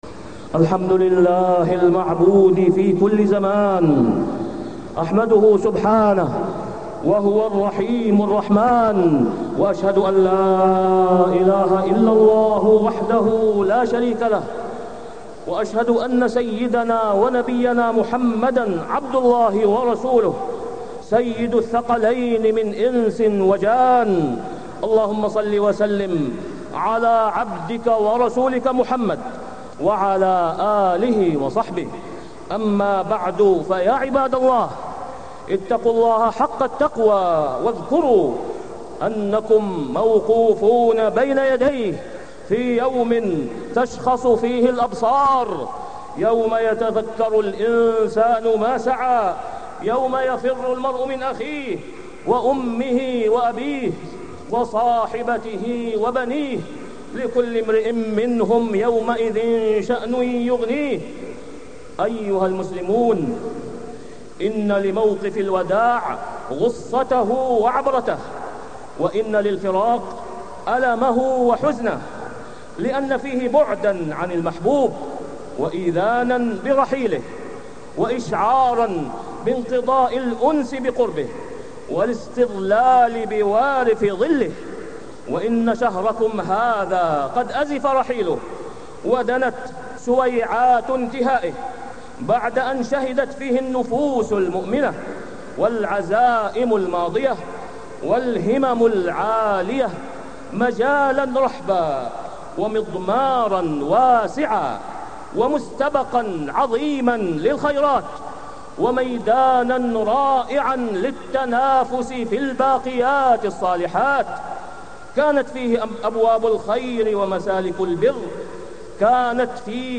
تاريخ النشر ٢٩ رمضان ١٤٢٥ هـ المكان: المسجد الحرام الشيخ: فضيلة الشيخ د. أسامة بن عبدالله خياط فضيلة الشيخ د. أسامة بن عبدالله خياط وداع رمضان